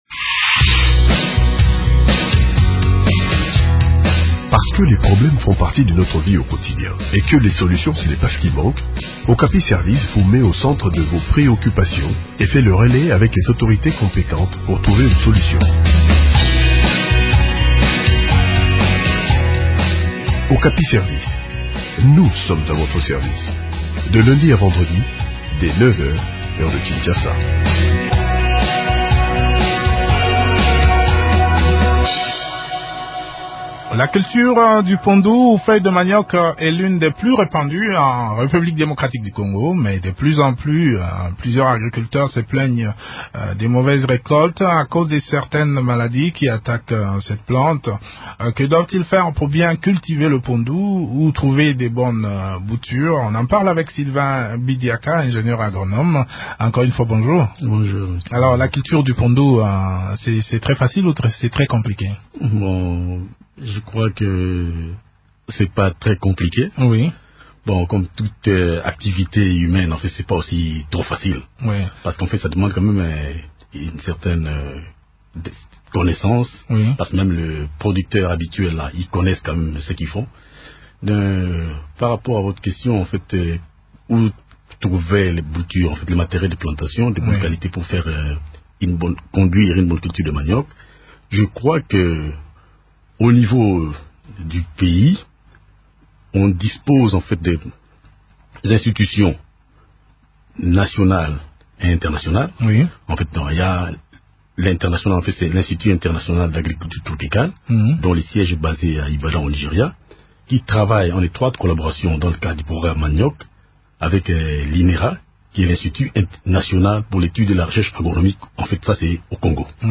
ingénieur agronome.